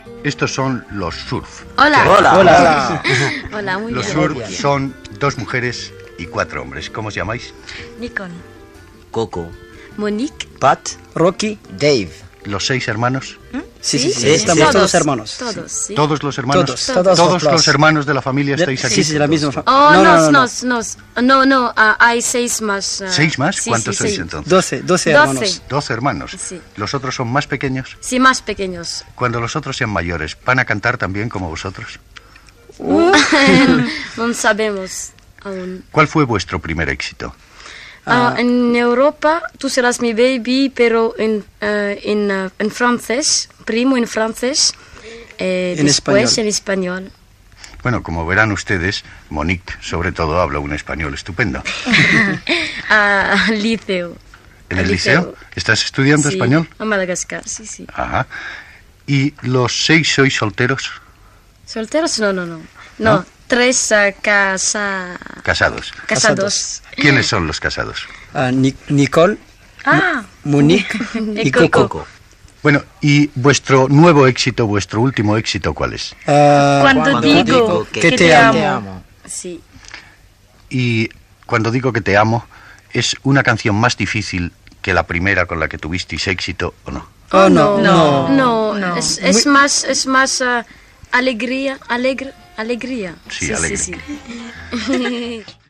Entrevista al grup musical Los Surf, integrat per sis germans de Madagascar
Fragment extret del programa "Audios para recordar" de Radio 5 emès el 31 de desembre del 2012.